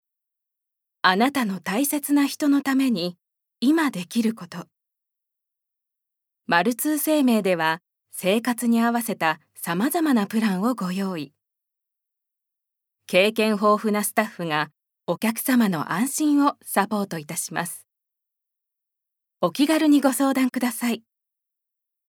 ボイスサンプル
ナレーション３